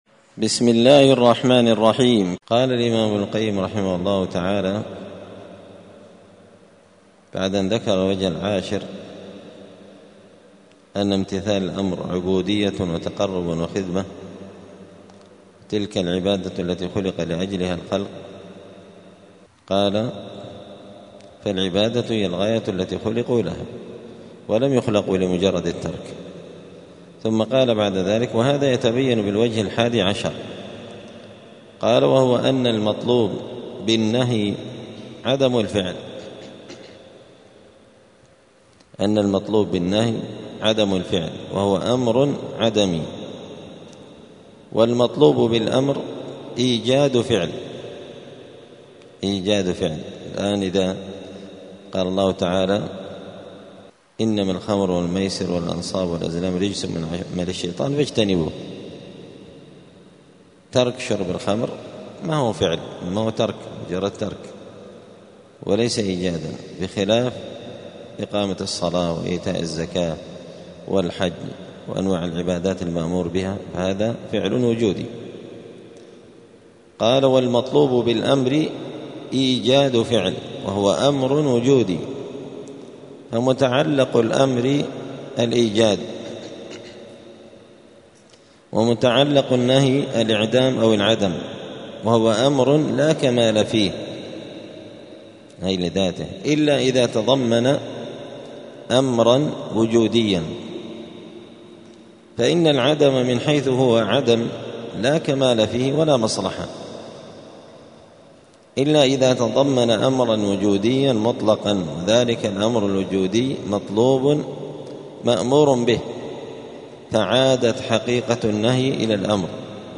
*الدرس السابع والستون (67) {فائدة: المطلوب بالنهي عدم الفعل والمطلوب بالأمر إيجاد الفعل}*